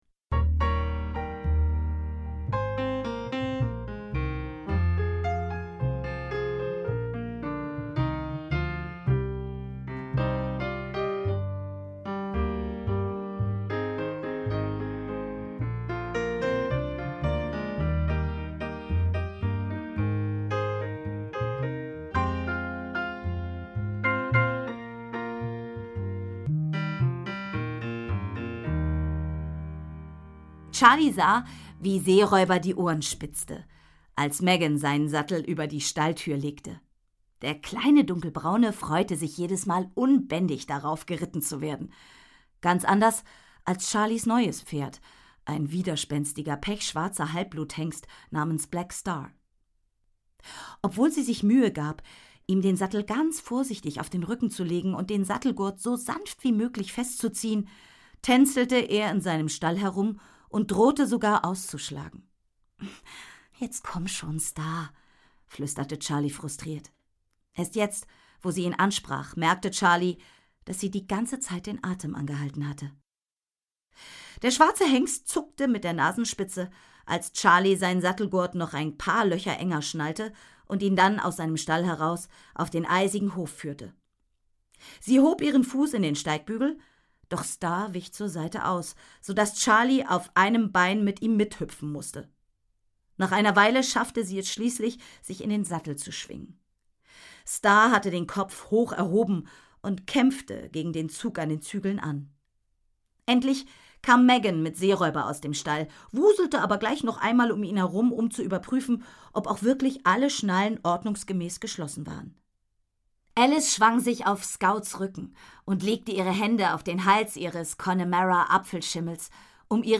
Hörbuch, 2 CDs, ca. 155 Minuten